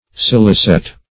Scilicet \Scil"i*cet\, adv. [L., fr. scire licet you may know.]